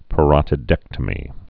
(pə-rŏtĭ-dĕktə-mē)